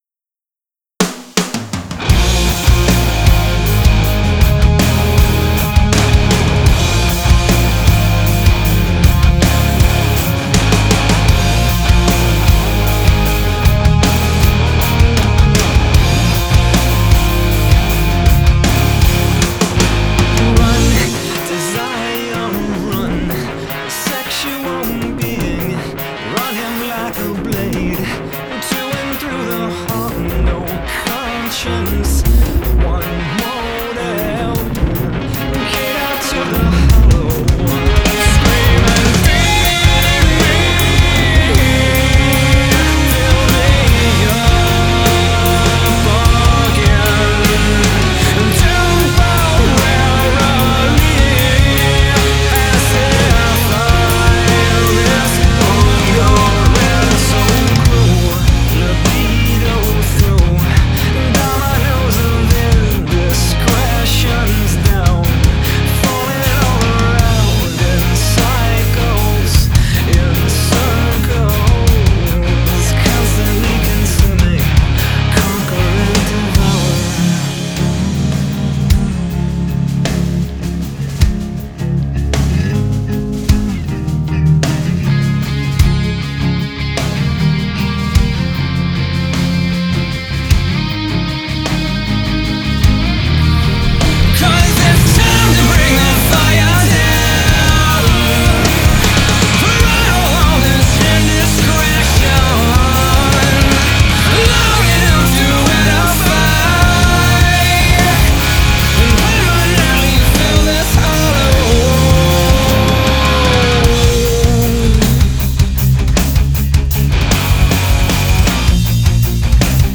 The recording is highly compressed, ‘loud’ with wide frequency range, so it could be difficult for an encoder.